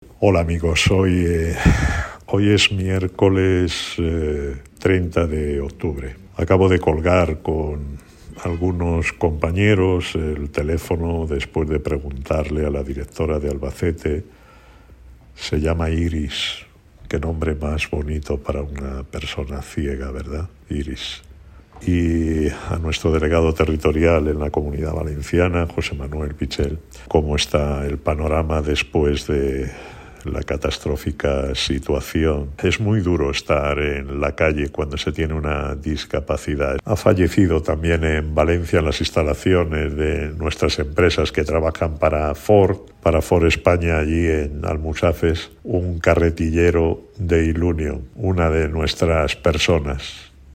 cuenta apesadumbrado formato MP3 audio(0,96 MB) la realidad de nuestra gente, nuestro trabajo de contacto con todos los hombres y mujeres de esta gran familia y